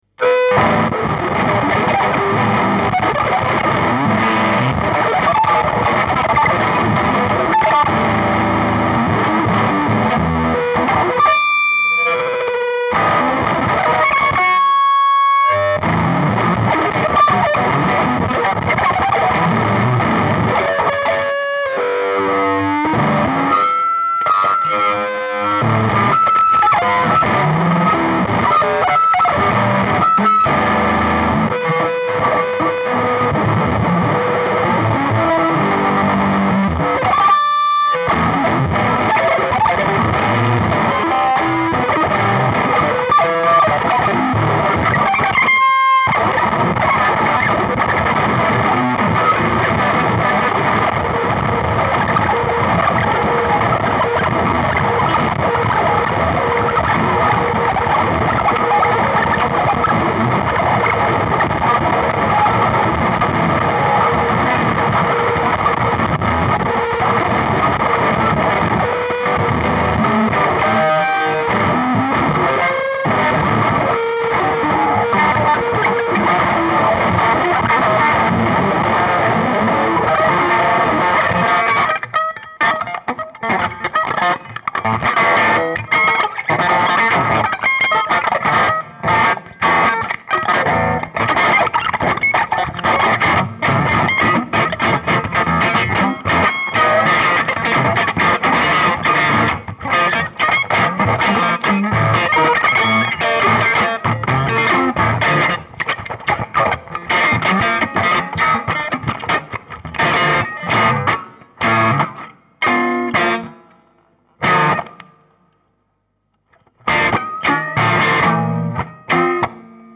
Proyecto de improvisación experimental